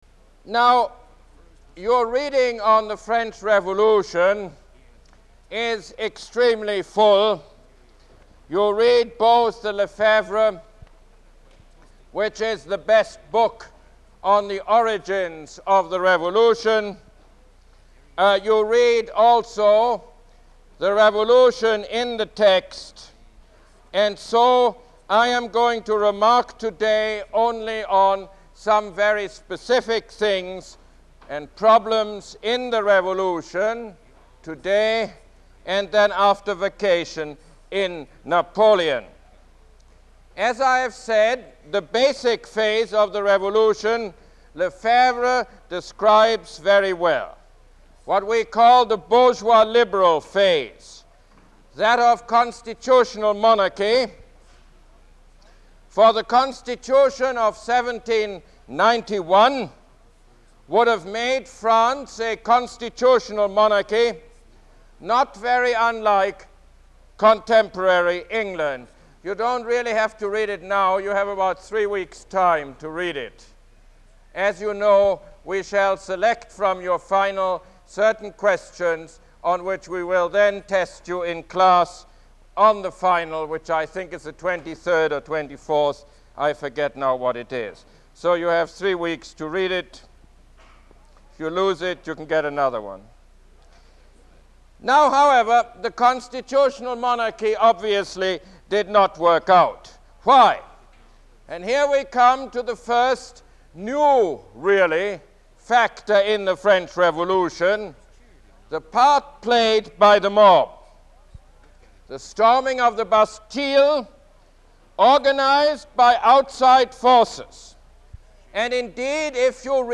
Lecture #24 - The French Revolution